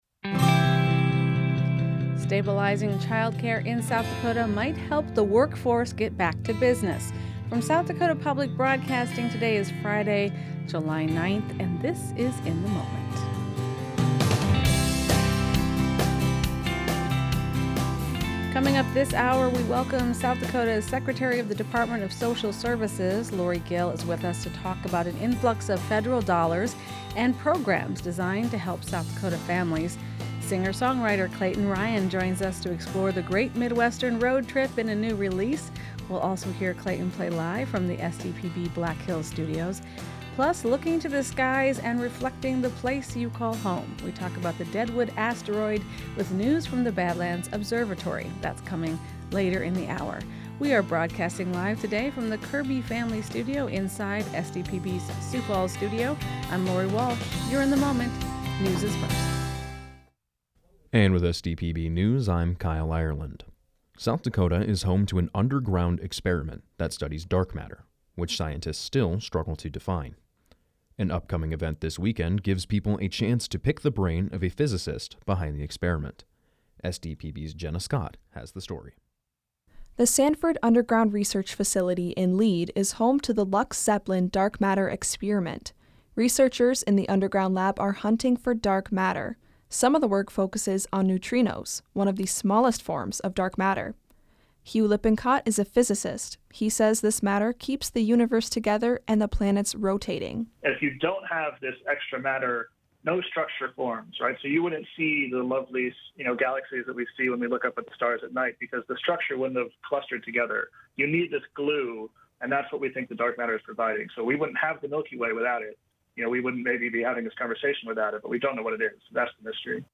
In the Moment is SDPB’s daily news and culture magazine program.
He'll play live from the SDPB Black Hills studios and offer a recorded new release as well. Poetry from Studio 47 features the complicated legacy of Rudyard Kipling.